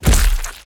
face_hit_finisher_73.wav